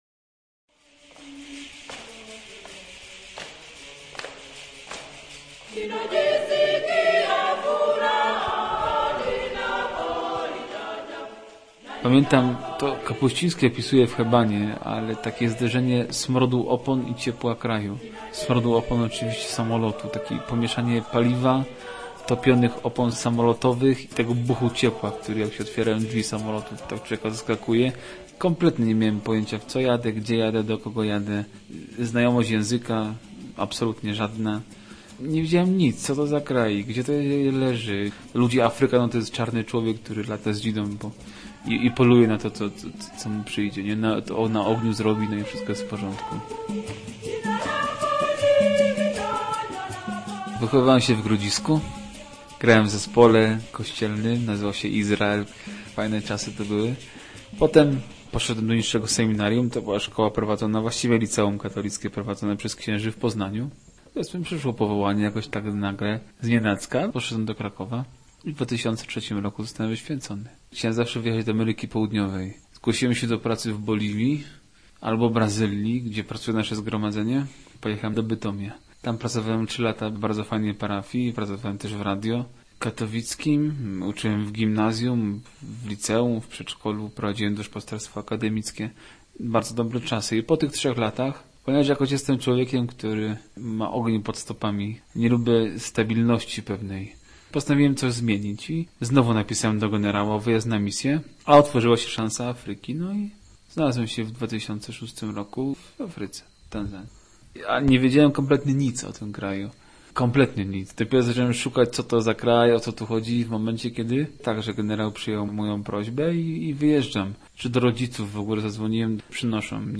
Ogień pod stopami - reportaż